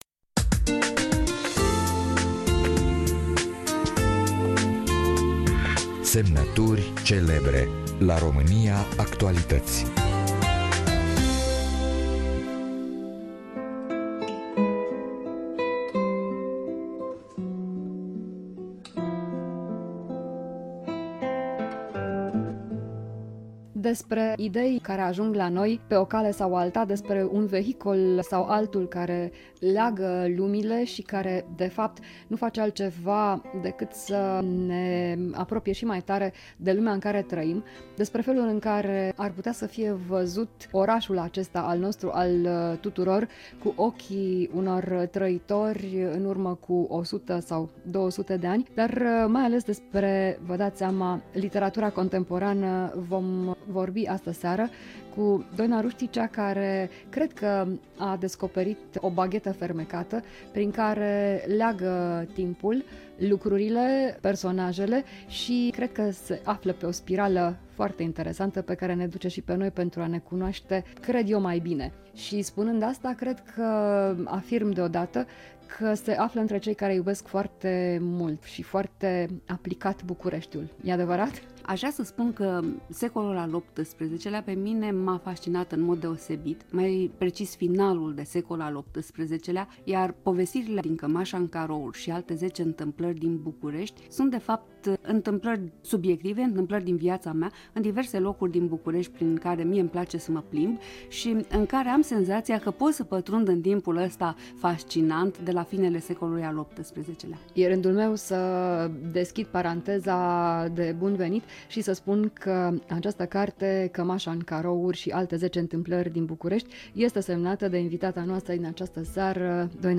🎙 Famous Signatures – Doina Ruști at Radio România Actualități